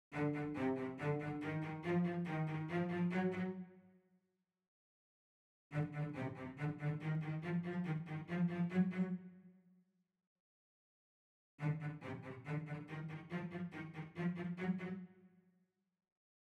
It contains 3 examples. The first is the present version 1.1 (as you have it), using the shortest possible settings.
The two further examples are played using an early version of our next update.
(Please note that, differently from your audio example, the attached example uses 44,1 kHz SR.)